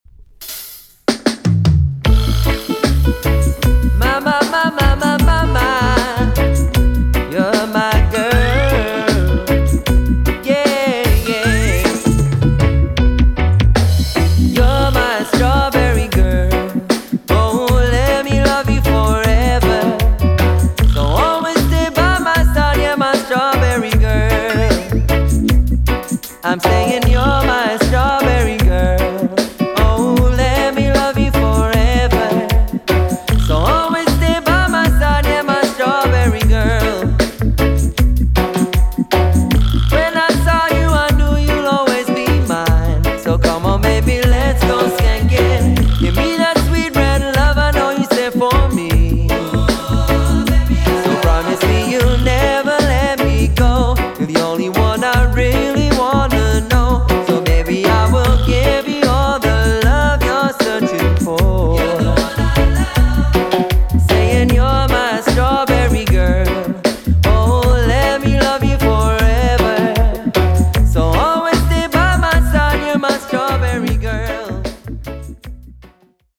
イスラエル出身の3人組によるロックステディ／ルーツ・レゲエ・バンド。
ヴィンテージなジャマイカン・サウンドをベースに、
親しみ易いメロディーとソウルフルで情熱的なパフォーマンスを武器に、